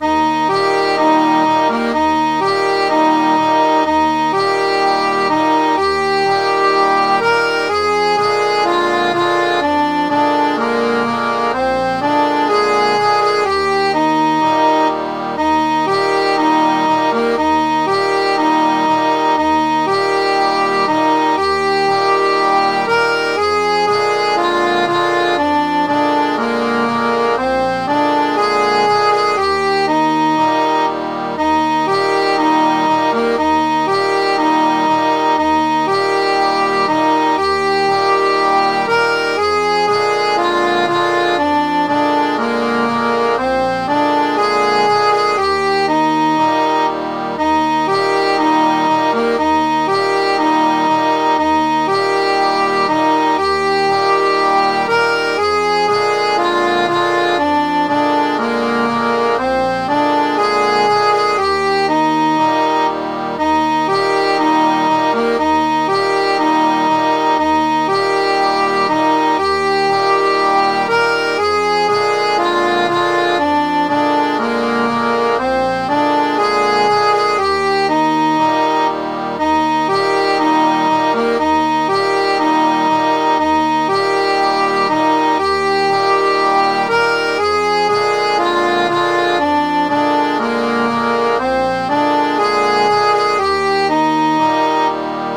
Midi File, Lyrics and Information to Whisky Johnnie